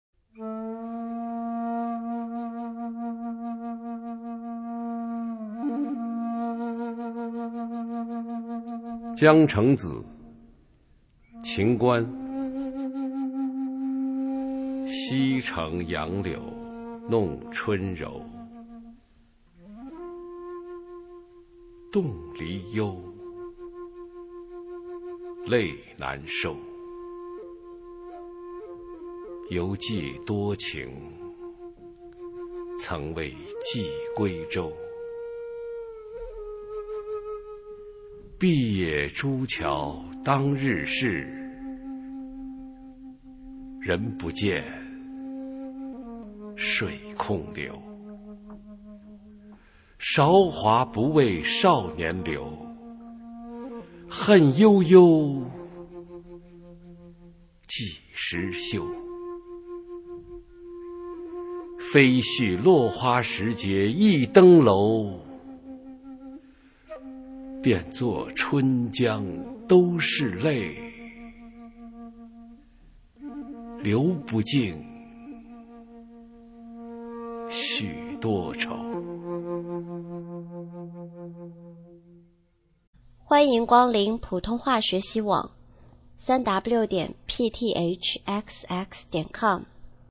首页 视听 学说普通话 美声欣赏
普通话美声欣赏：江城子